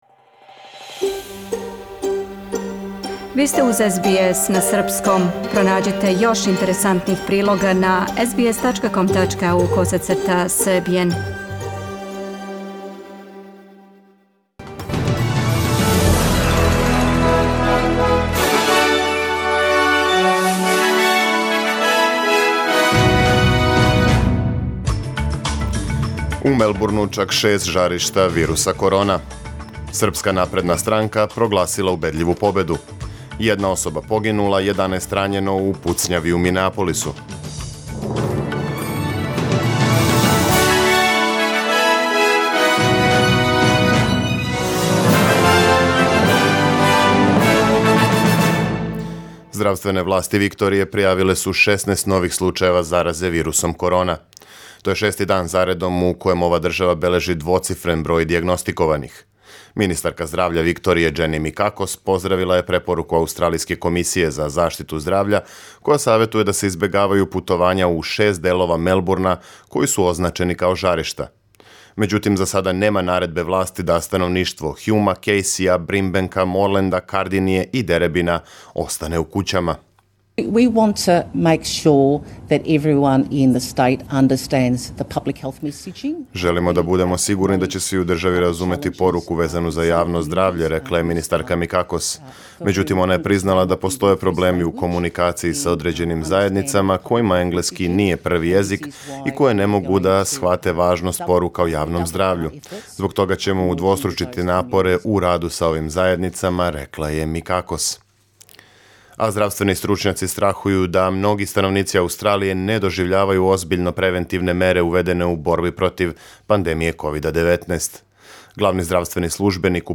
Преглед вести за 22. јун 2020.